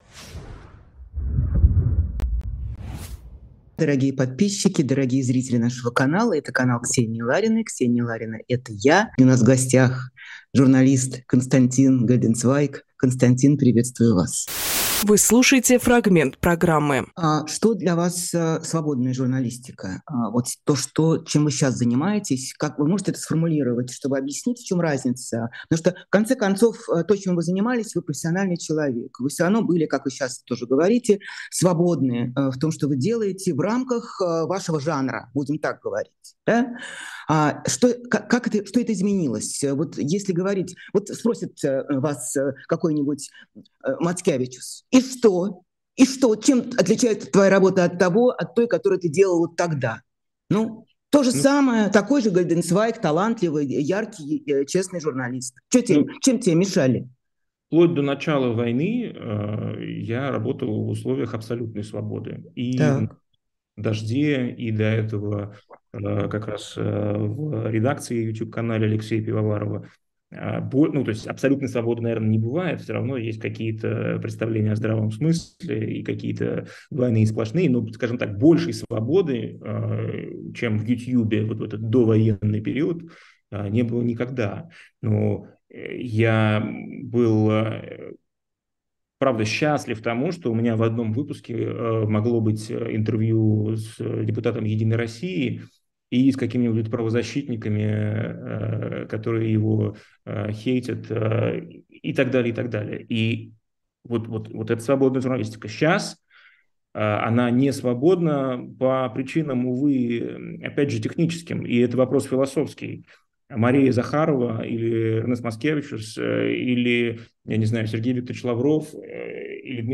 Фрагмент эфира от 10.06